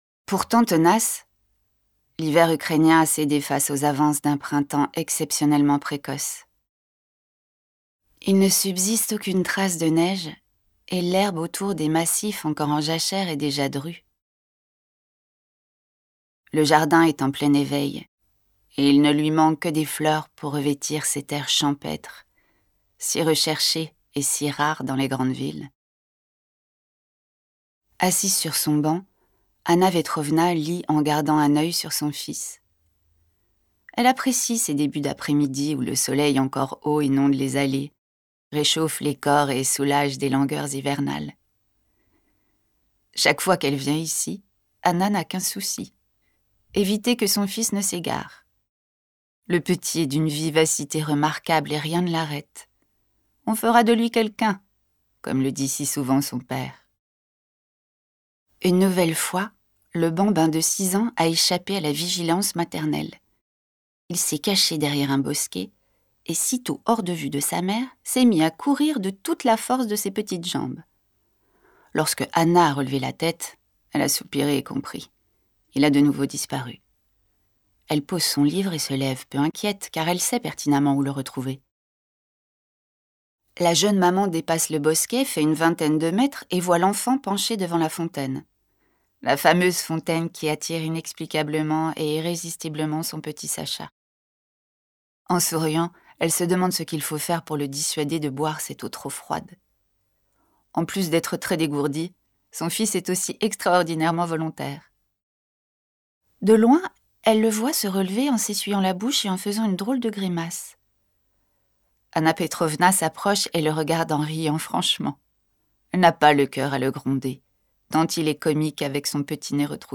LIVRE AUDIO (L'offrande des fous) – narration - suspens